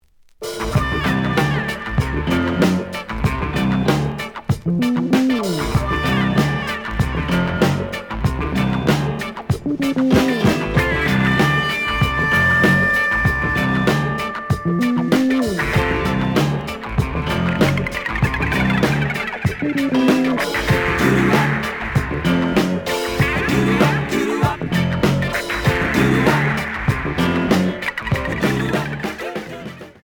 The audio sample is recorded from the actual item.
●Genre: Funk, 70's Funk
Slight edge warp.